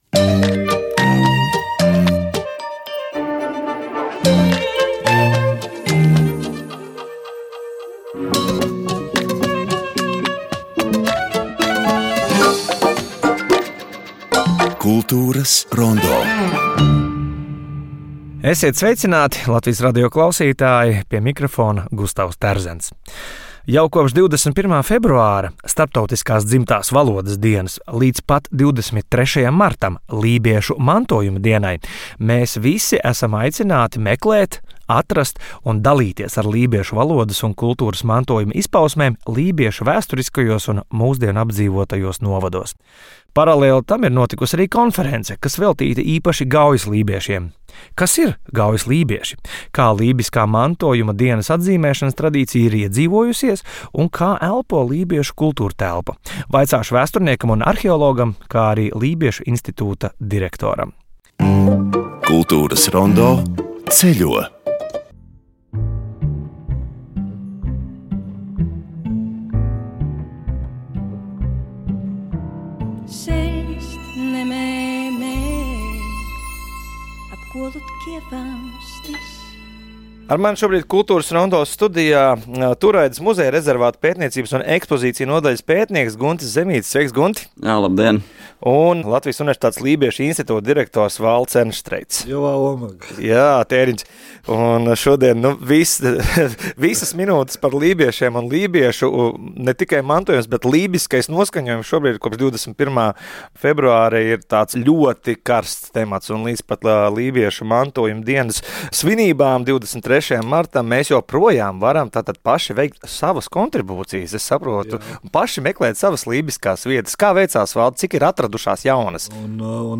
Ko un kā mums palīdz saprast kultūras periodika: saruna ar izdevumu pārstāvjiem.